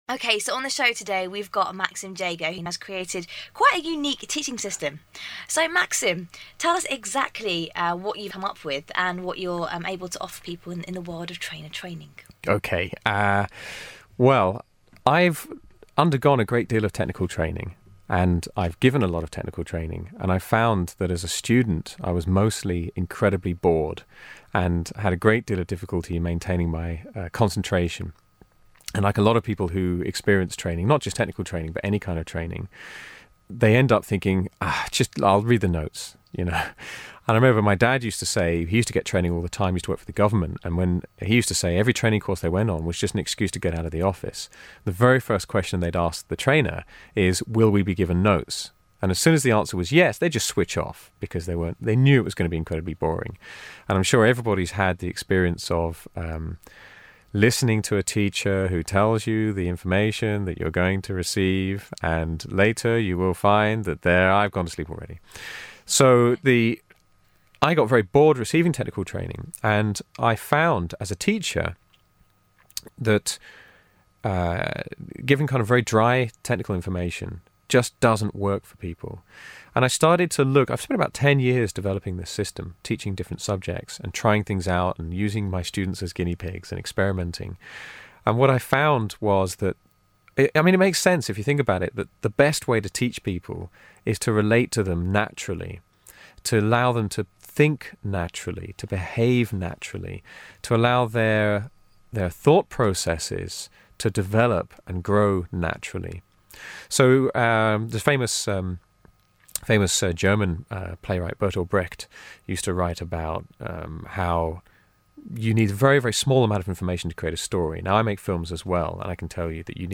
ESPinterview.mp3